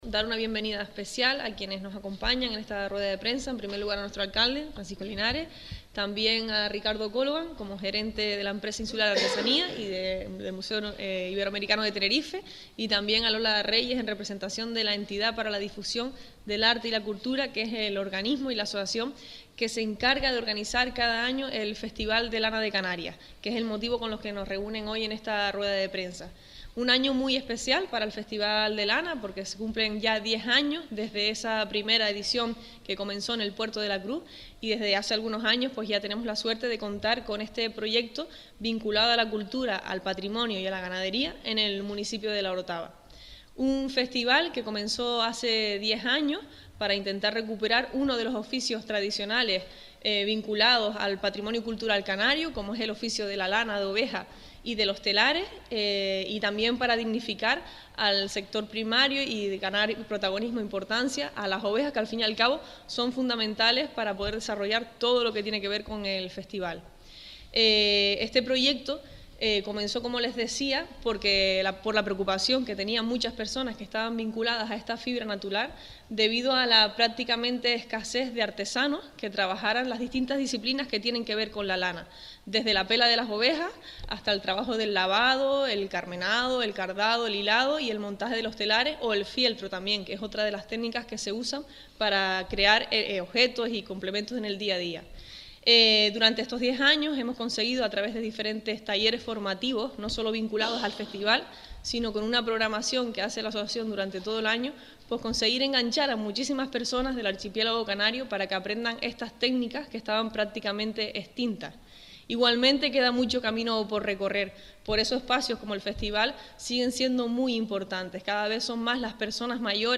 Intervención en ‘ Las mañanas’ con Gente Radio